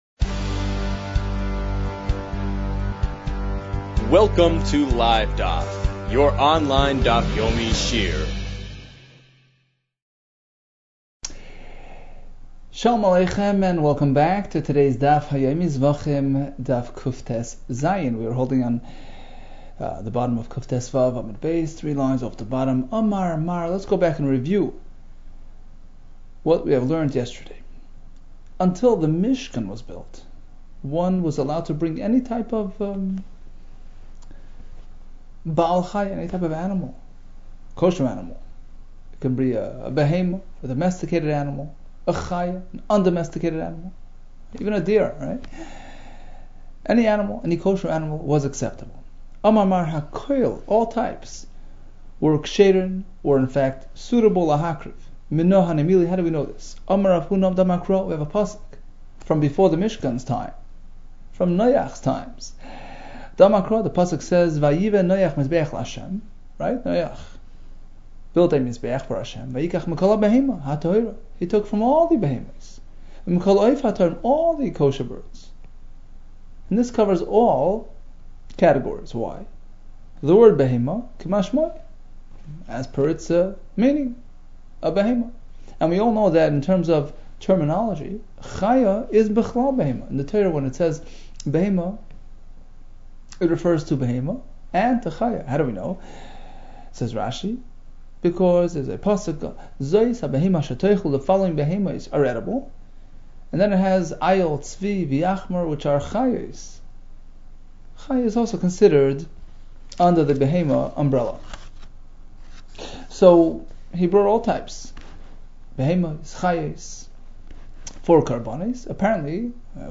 Zevachim 116 - זבחים קטז | Daf Yomi Online Shiur | Livedaf